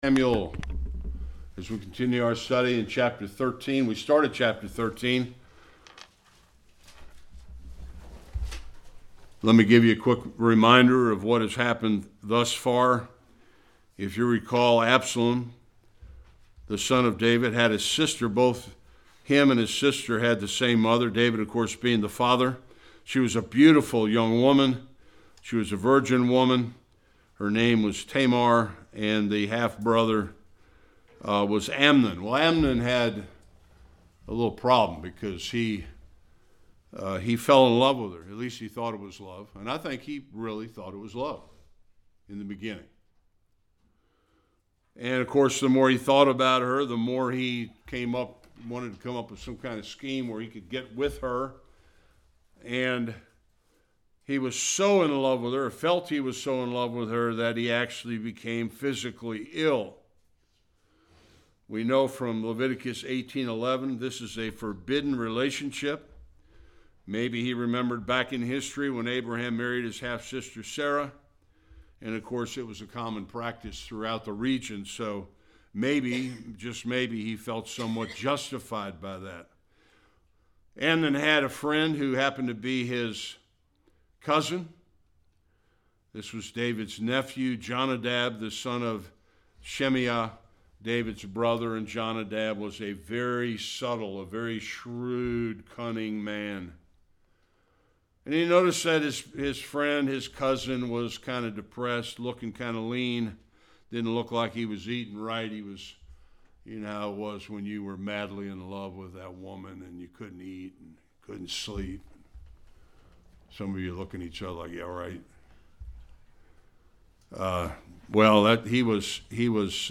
1-29 Service Type: Sunday School The tragic account of Tamar’s rape by her half-brother Amnon.